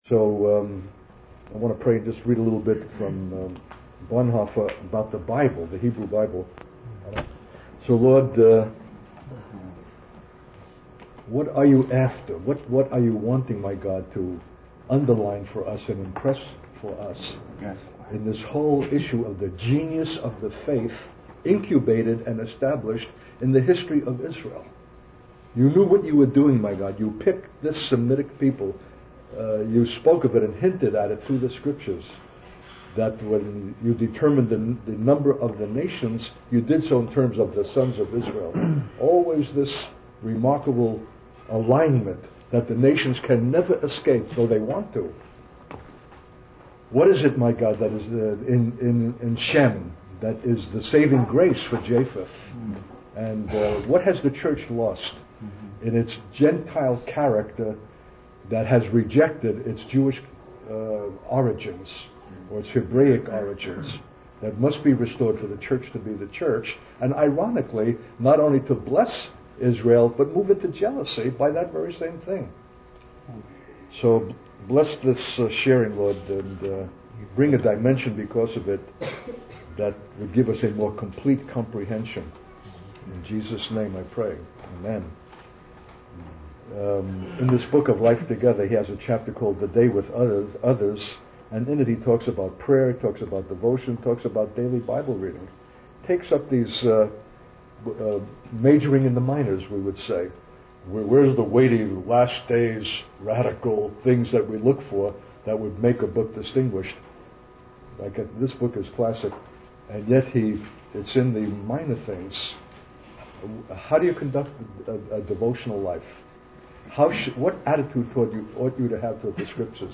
In this sermon, the speaker emphasizes the importance of quality of spirit in our relationship with God, rather than just focusing on the passage of time. He highlights the significance of sacred history as a means of understanding righteousness and humility through God's acts. The speaker also discusses the value of reading scripture consecutively, as it allows us to immerse ourselves in the experiences of our ancestors and learn from their doubts, unbelief, punishment, repentance, and God's faithfulness.